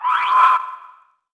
Faerie Dragon Yells
精灵龙叫声